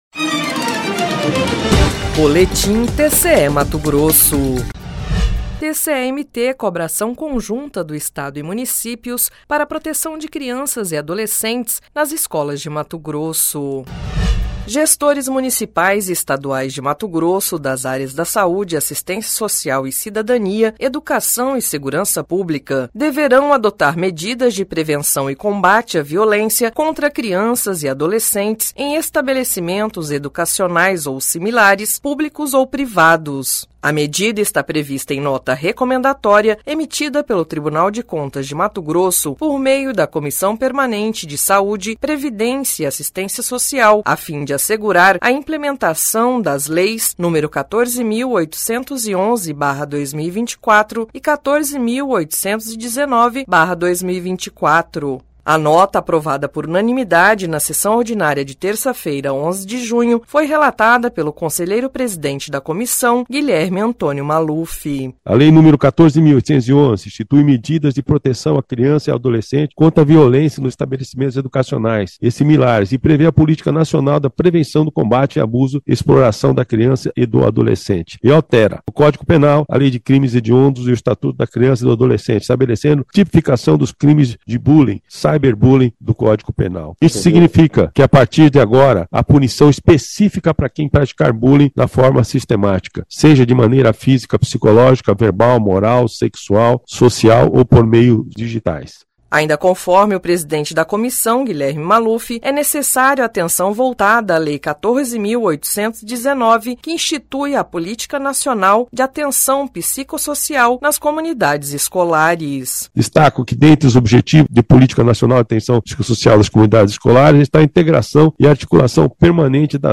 Sonora: Guilherme Antonio Maluf – conselheiro presidente da COPSPAS